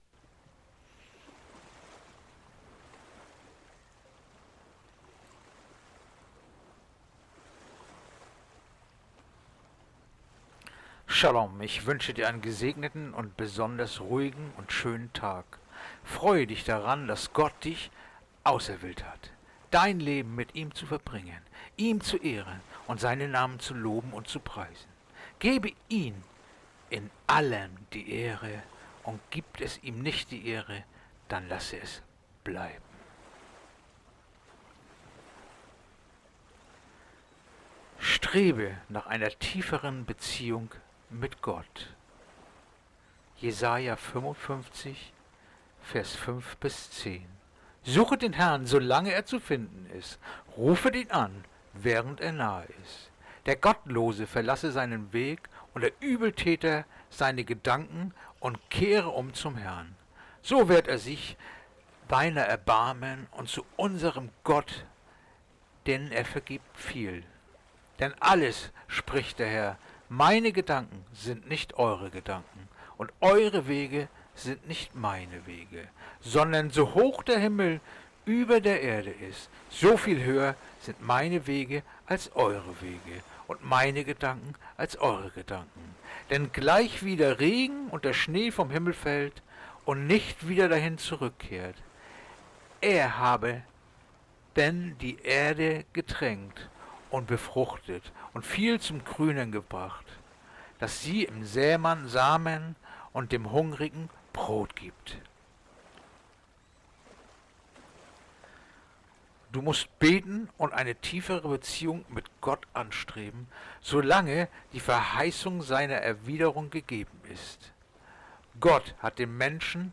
Andacht-vom-02-Juli-Jesaja-55-5-10